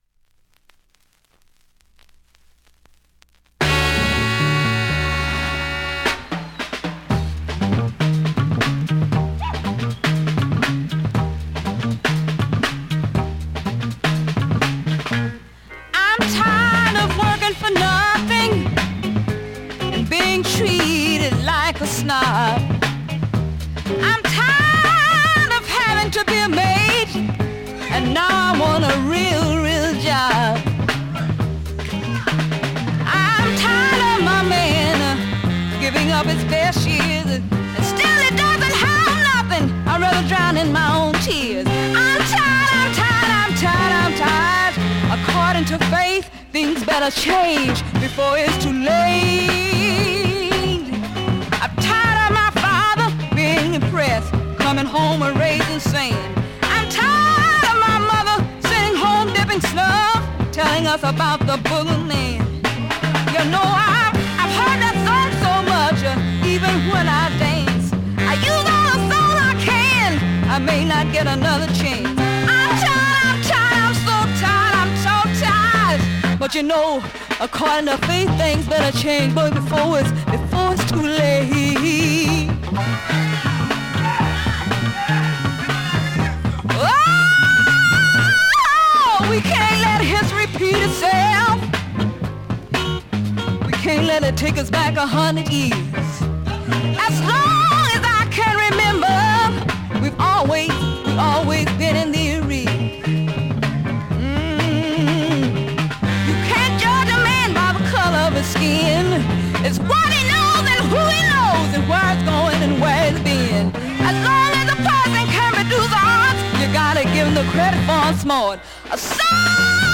◆USA盤 オリジナル 7"Single 45 RPM
現物の試聴（両面すべて録音時間５分２９秒）できます。